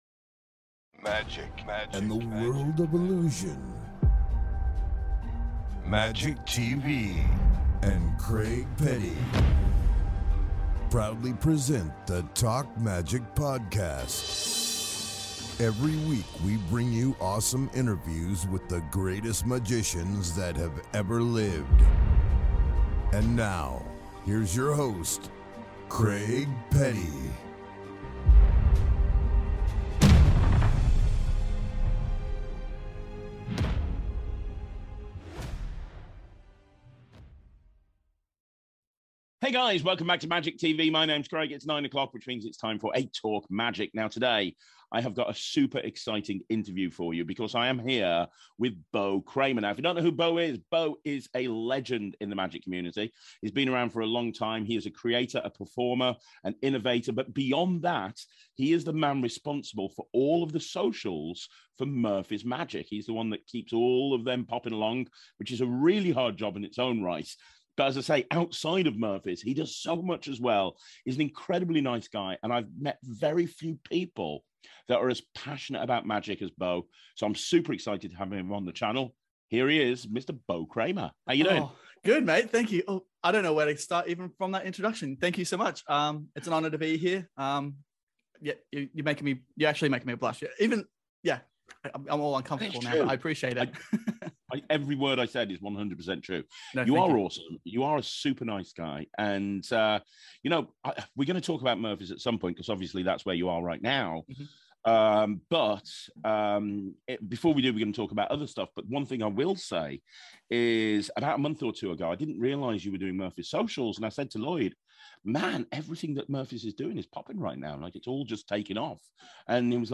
This is a great interview and not one you should miss.